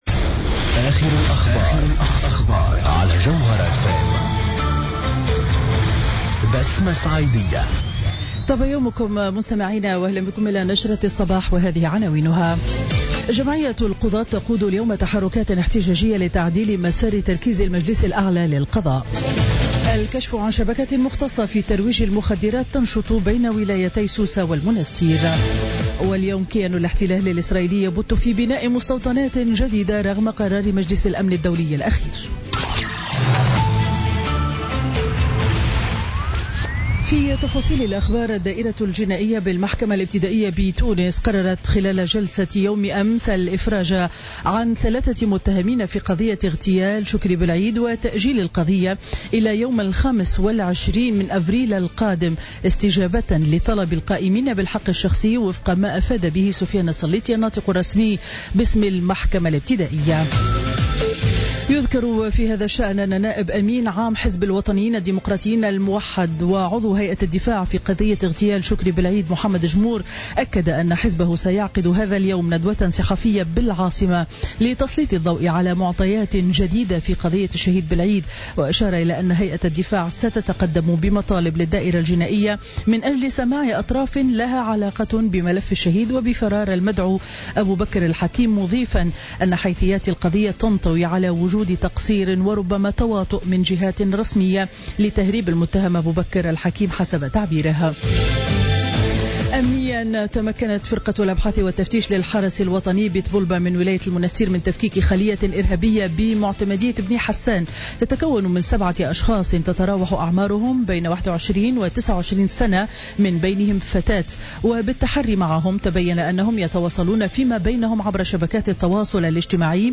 نشرة أخبار السابعة صباحا ليوم الأربعاء 28 ديسمبر 2016